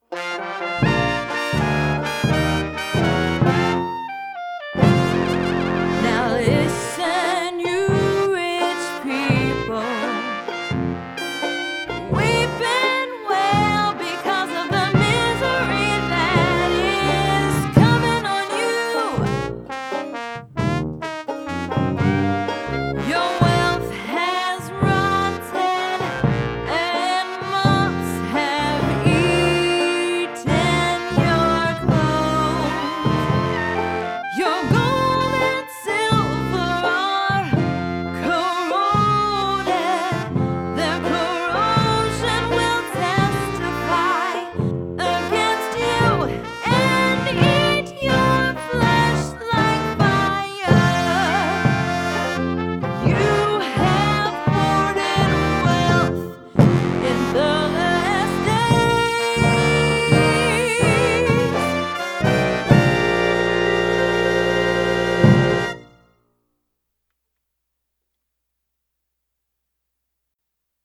Listen to fun songs designed to aid memorization, complete family serving opportunities to put your faith into action, and be in our James Gang poster on the “Wall of Fame” at the end of the year (picture will be taken on May 14, 2014 here at Calvary Church).
We have created songs of James 5 set to music to help kids memorize.